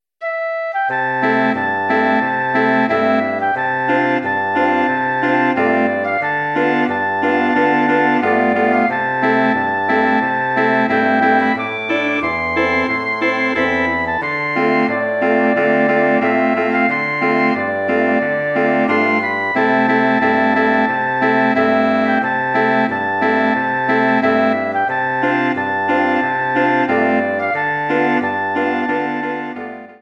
Bearbeitung für Bläserquintett
Arrangement for woodwind quintet